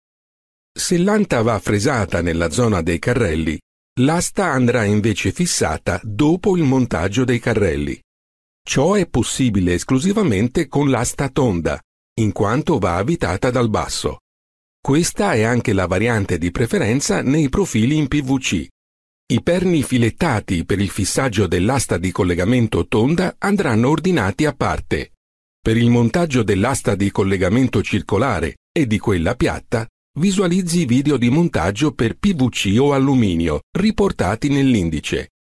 Profonde, Chaude, Corporative, Commerciale, Senior, Mature
E-learning